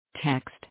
Also added a tts api in the same file using: